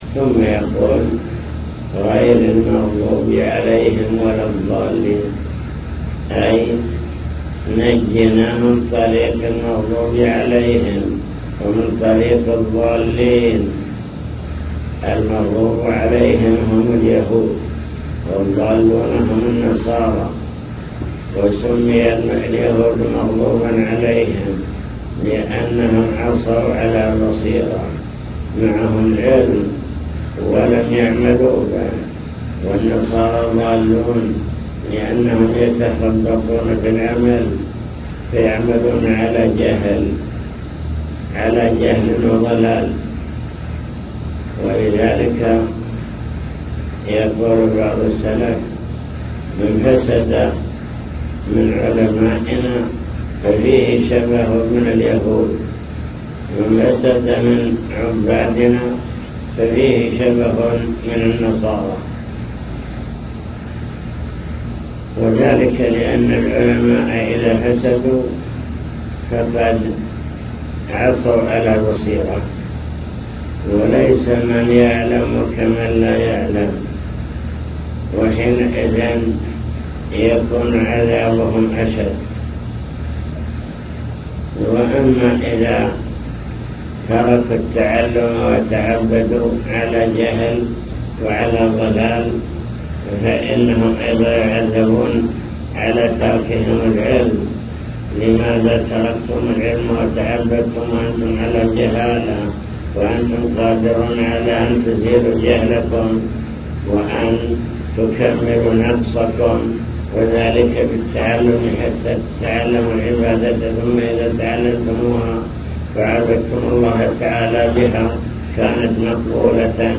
المكتبة الصوتية  تسجيلات - لقاءات  حول أركان الصلاة (لقاء مفتوح)